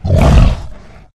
boar_aggressive_3.ogg